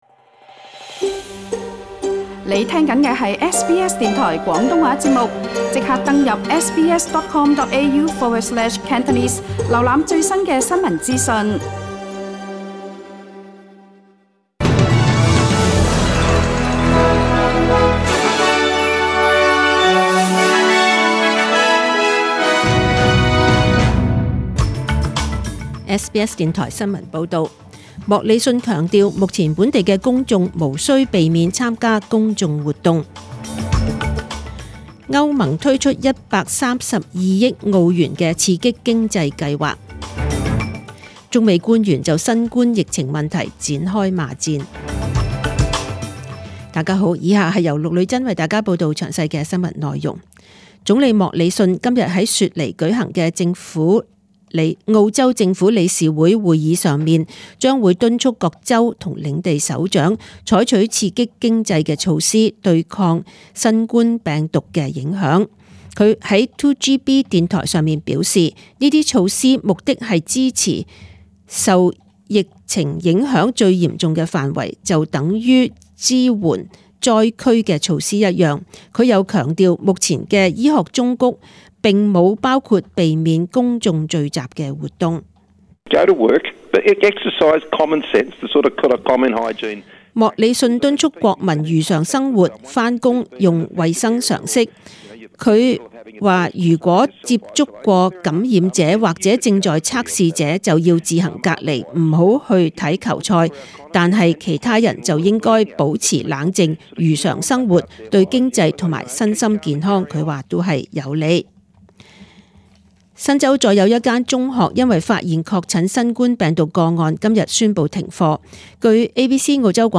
请收听本台为大家准备的详尽早晨新闻